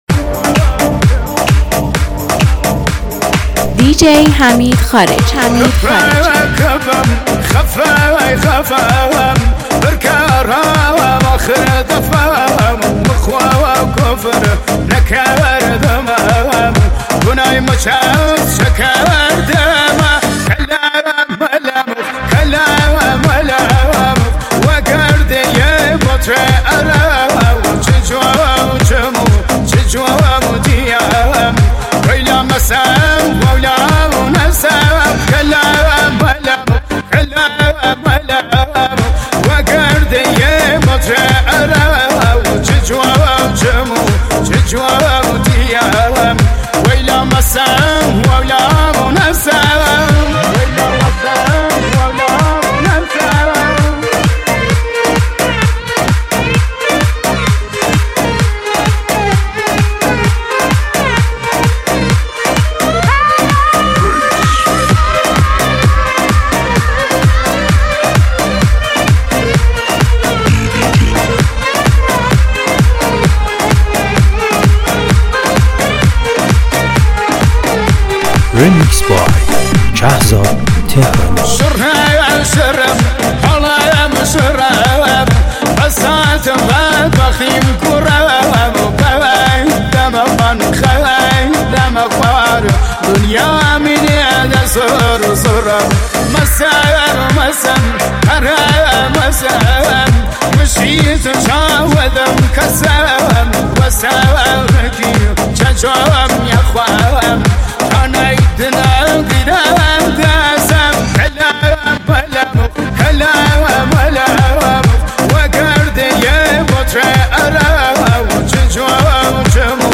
بیس دار تند تکنو سیستمی
ریمیکس تند شاد سیستمی خفن